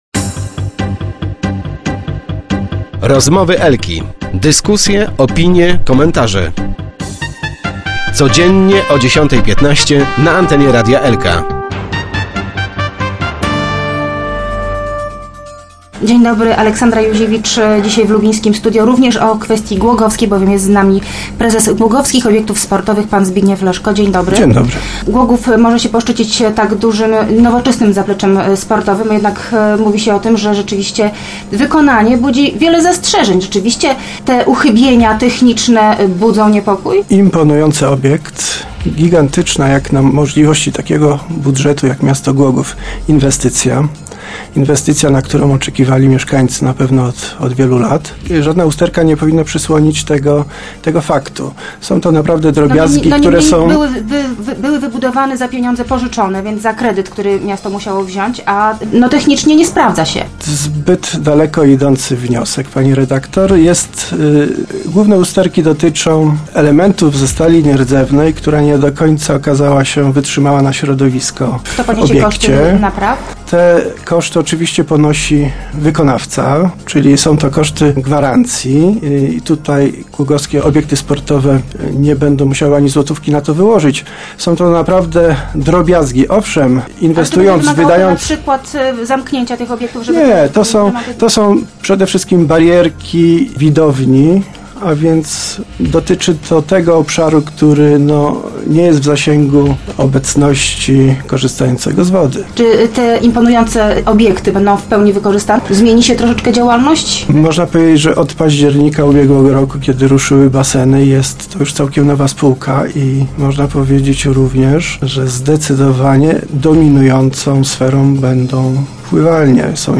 W radiowym studiu w Rozmowach Elki gościł dziś